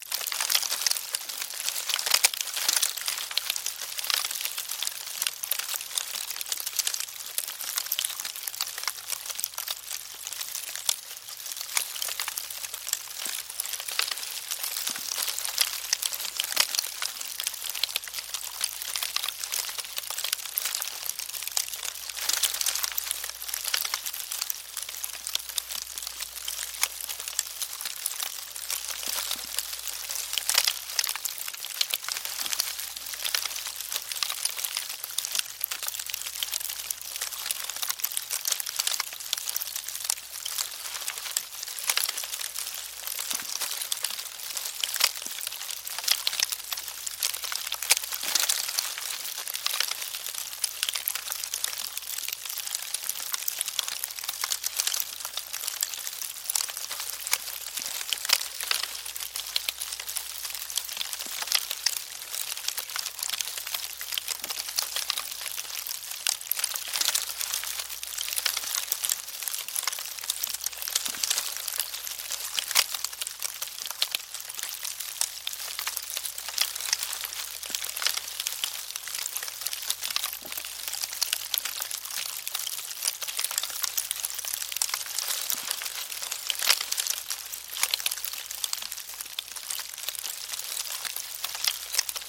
На этой странице собраны звуки термитов – от тихого шуршания до характерного постукивания.
Звук поедания деревянного дома множеством термитов